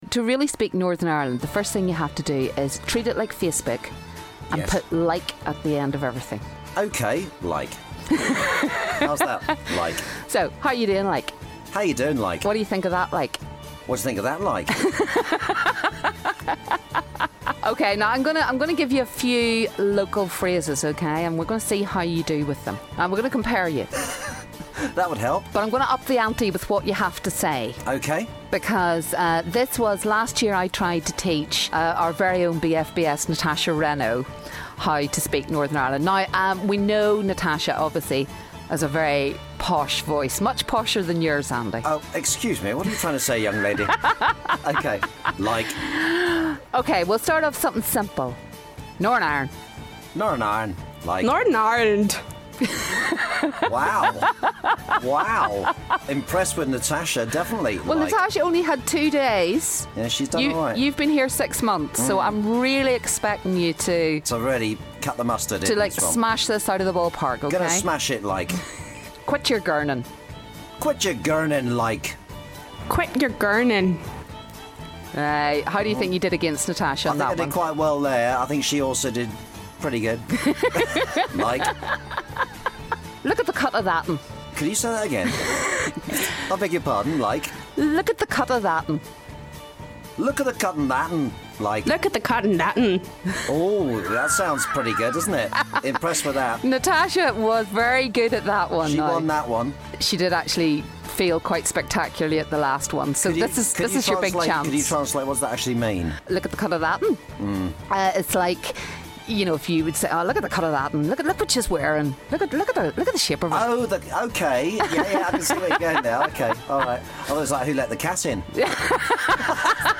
TRYING to speak Northern Ireland Like!